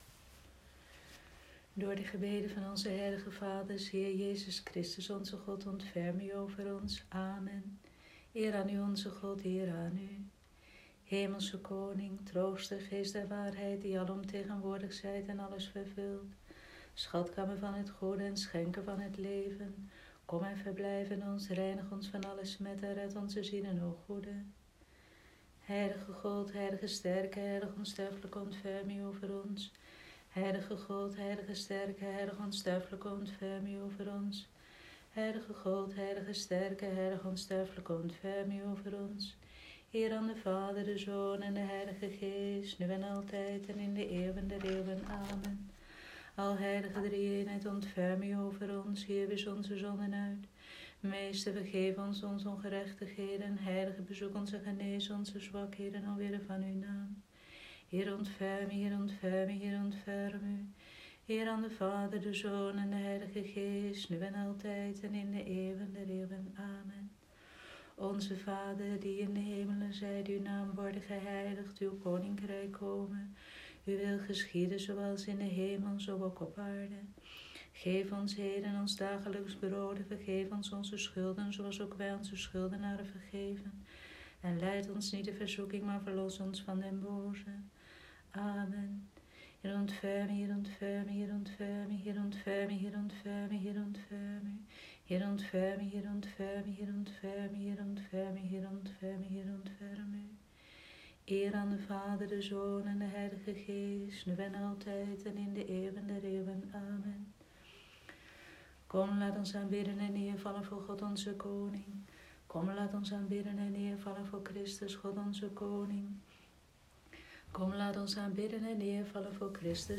Vespers van Dinsdagavond, 31 Maart 2020